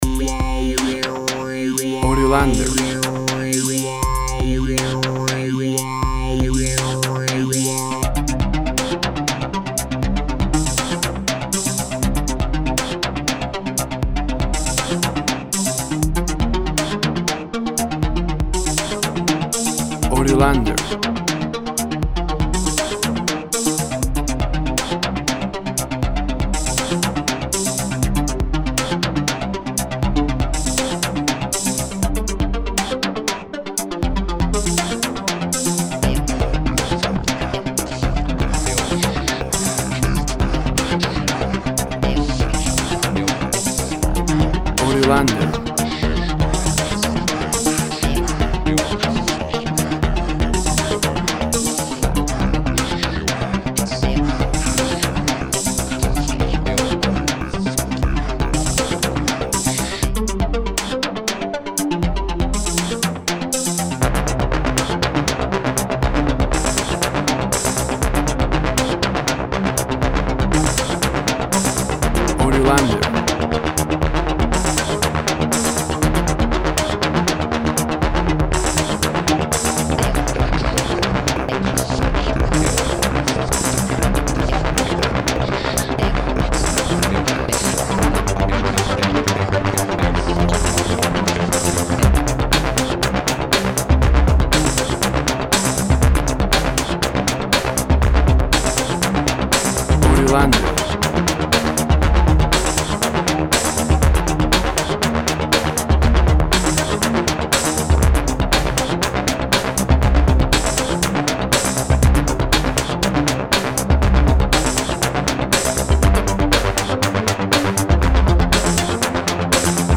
Cinematic run, SCI-FI music.
Tempo (BPM) 120